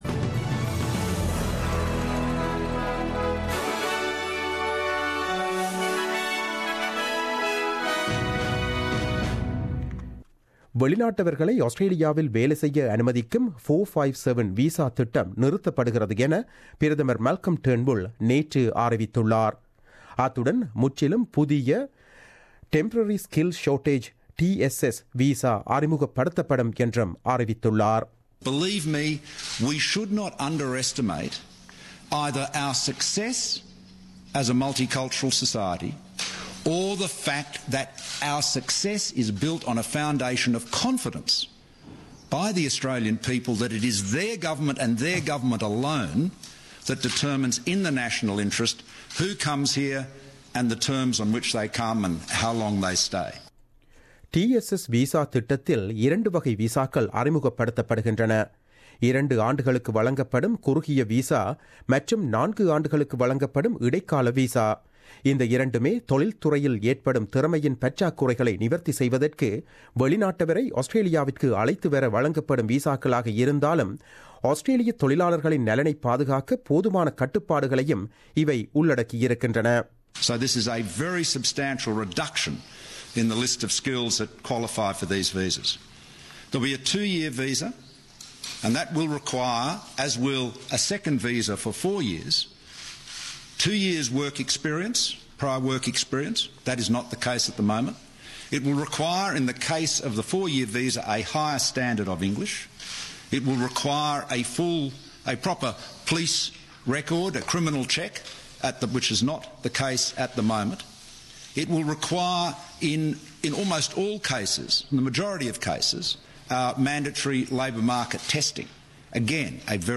The news bulletin aired on Wednesday 19 April 2017 at 8pm.